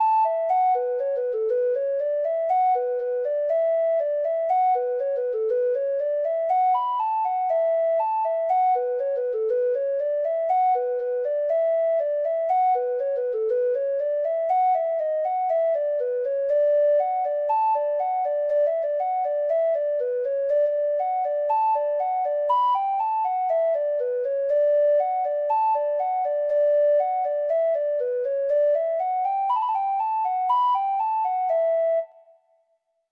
Traditional Music of unknown author.
Reels